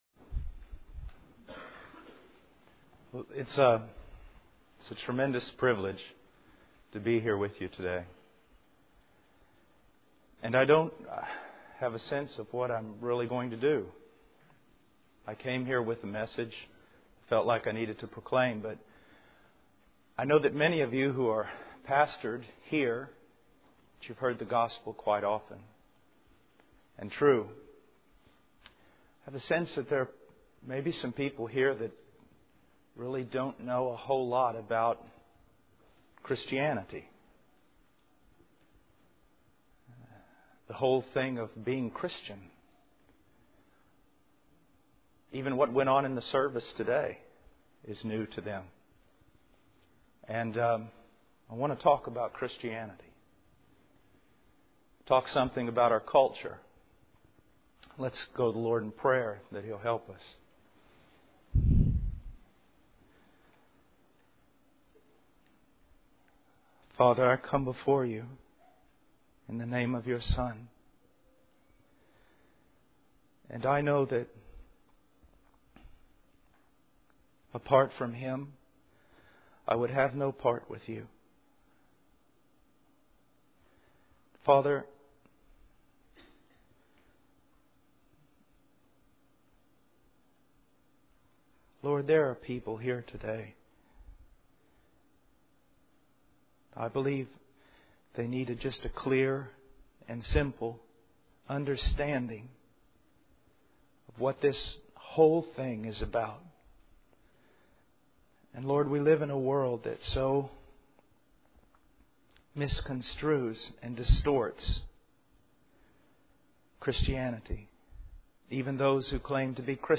Wise Desire Ministries helps convey various Christian videos and audio sermons.